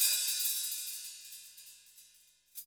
HIHAT OP 9.wav